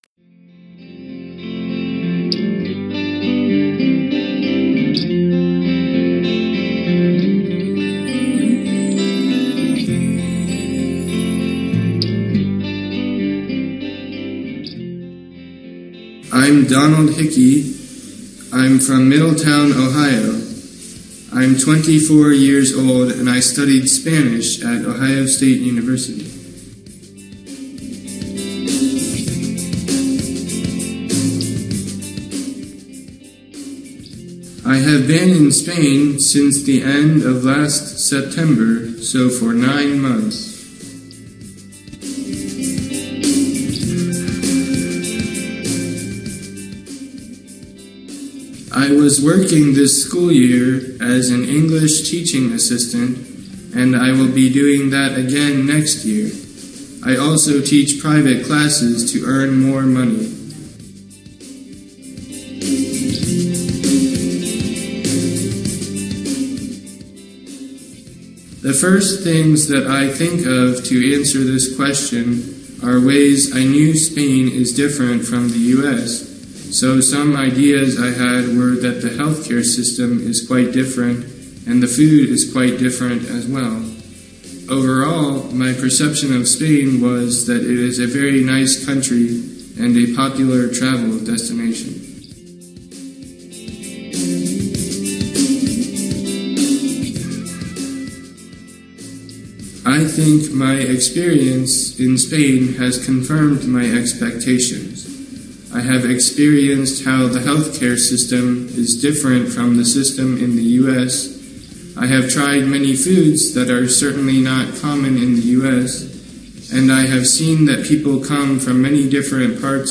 MULTIPLE CHOICE: AN INTERVIEW